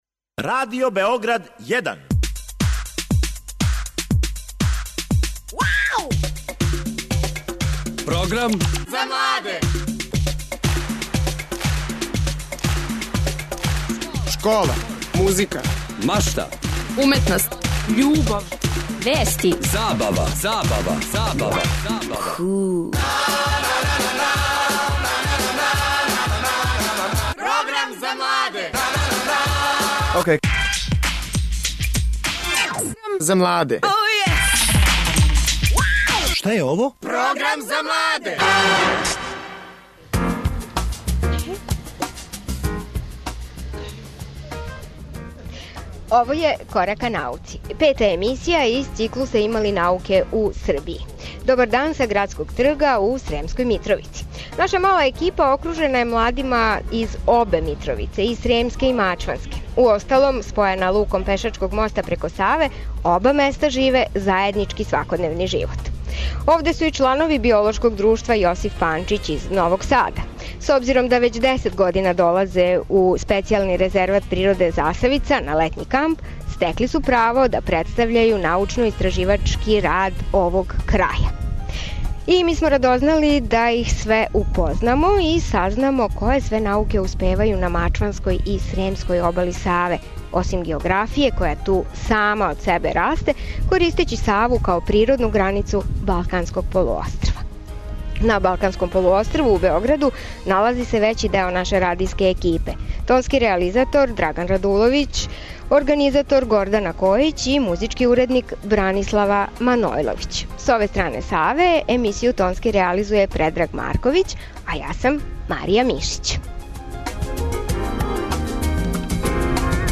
Наши гости биће људи који се старају о овом природном добру - покрет Горана из Сремске и Мачванске Митровице.
Емисија се емитује из града, али ми ћемо отићи до Засавице, посетићемо даброве и потрудити се да вам верно пренесемо слику резервата.